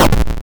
break.wav